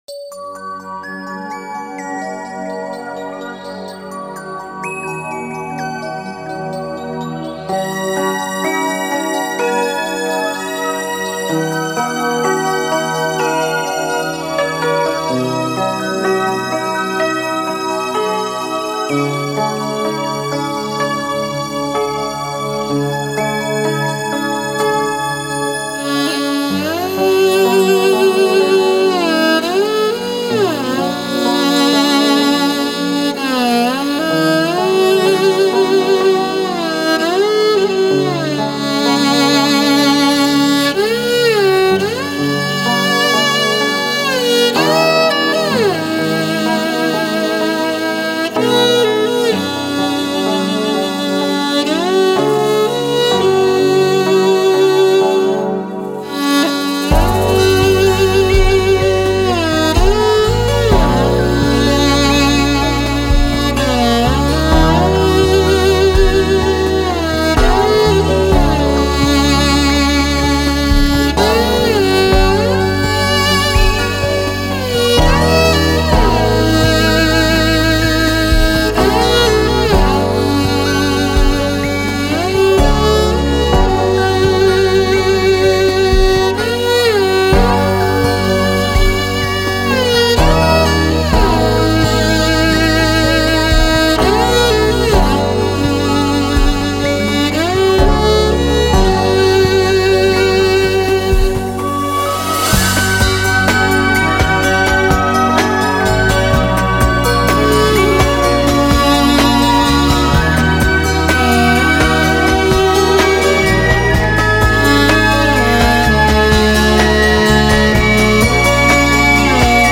灵魂的乐器--马头琴
《四季》是根据同名民歌改编的，曲调悠扬流畅，描绘了锡林郭勒大草原一年四季自然景色的变化和牧民对家乡的热爱